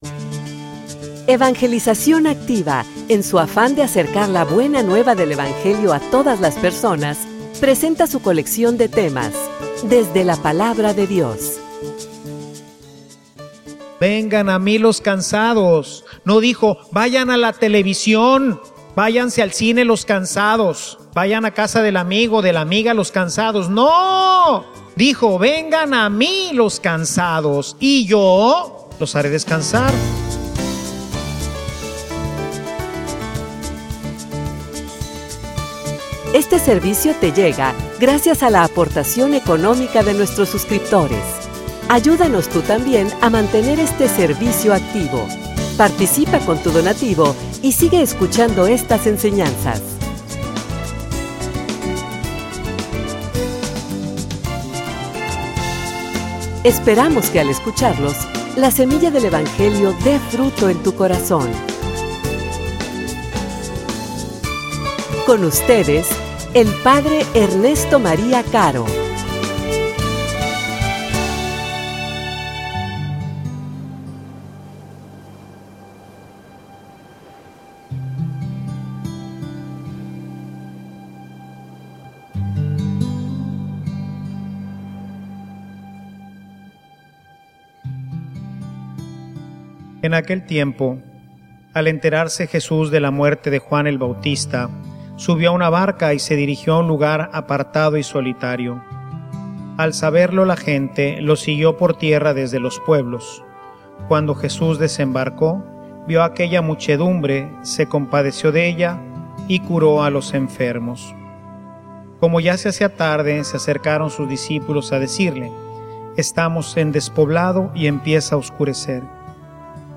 homilia_Como_usas_tu_tiempo_libre.mp3